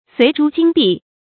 隋珠荆璧 suí zhū jīng bì 成语解释 即隋珠和璧。
ㄙㄨㄟˊ ㄓㄨ ㄐㄧㄥ ㄅㄧˋ